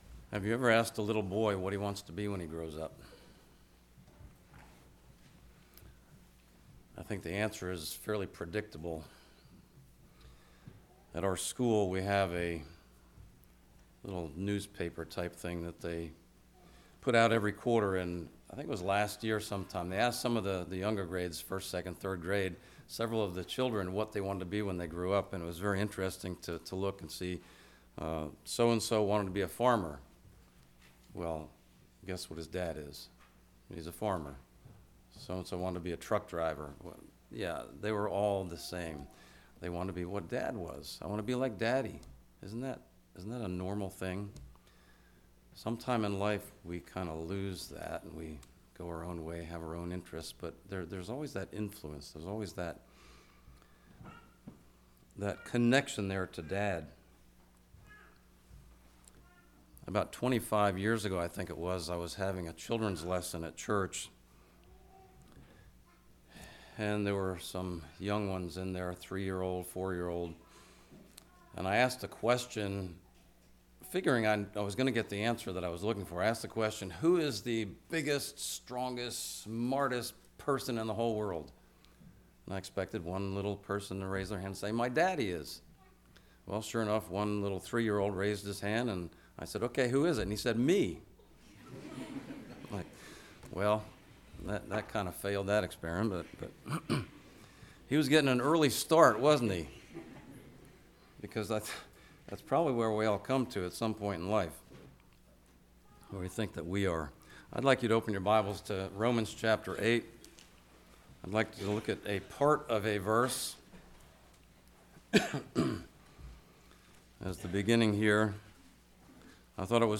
Congregation: Leola
Sermon